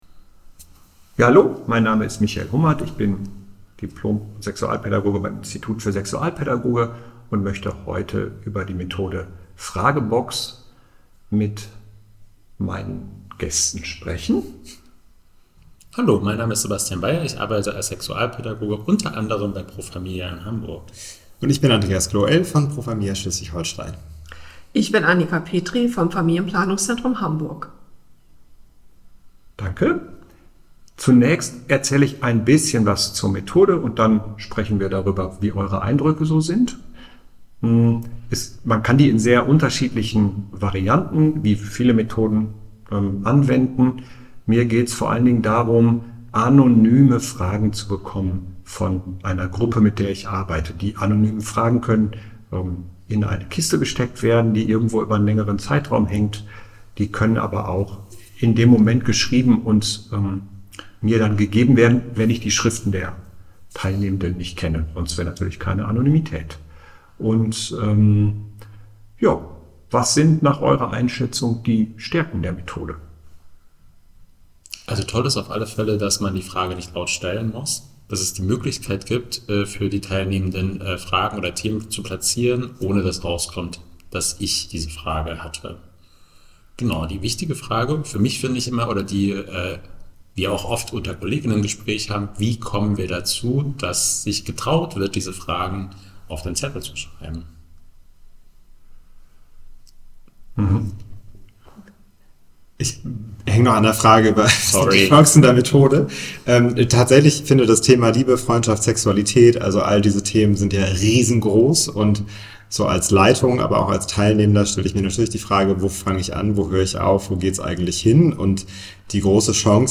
Podcast_Methodendiskussion_Zettelkasten.mp3